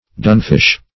Search Result for " dunfish" : The Collaborative International Dictionary of English v.0.48: Dunfish \Dun"fish\, n. Codfish cured in a particular manner, so as to be of a superior quality.